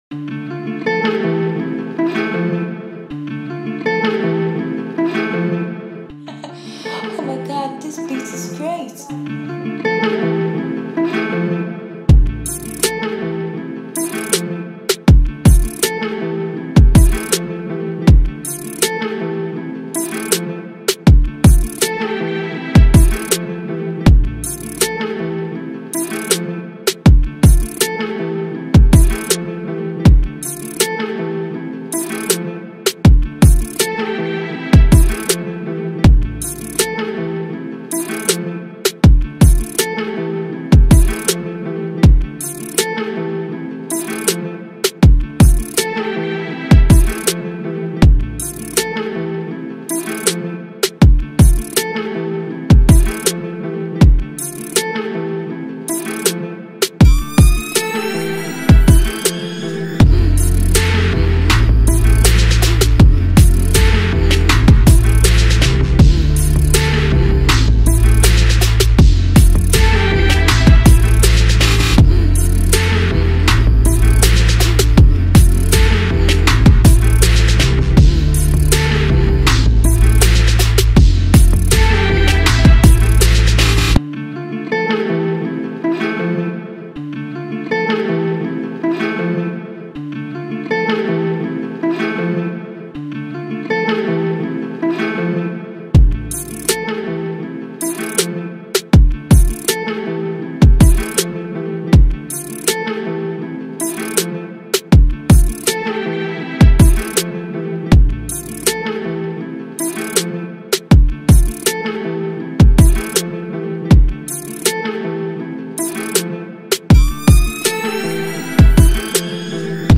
Afropop instrumental